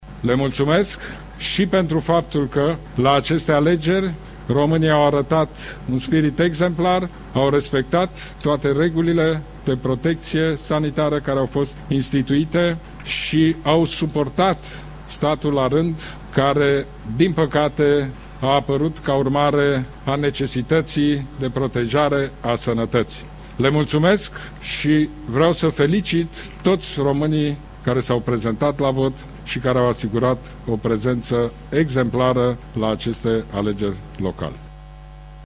Pe de altă parte, premierul Orban a afirmat că la aceste alegeri românii au arătat un spirit exemplar și i-a felicitat pe toți cei care s-au prezentat la vot: